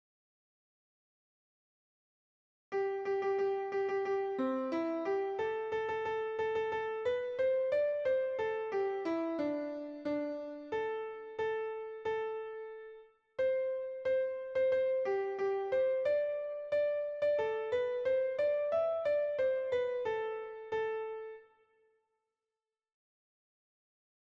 Tenor 2